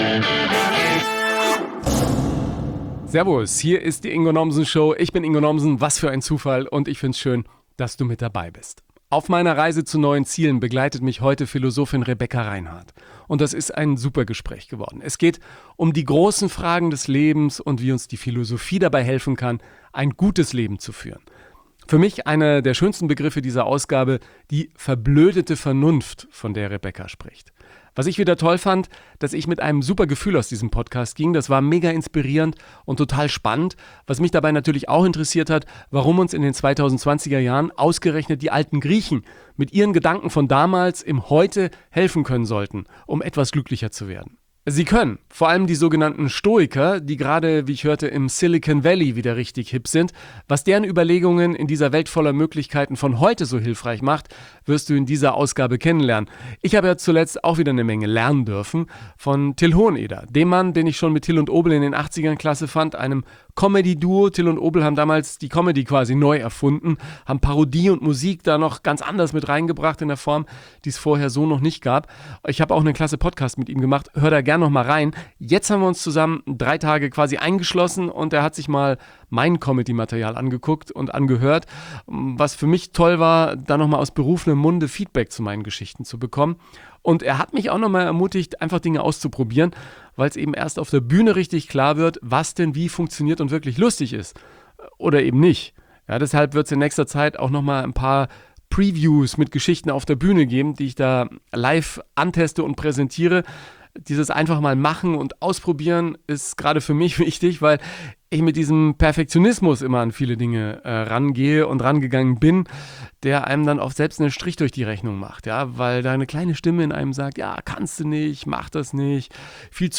Und das tun wir dort, wo man sich wirklich begegnet – auf einen Coffee to go, draußen in der freien Wildbahn.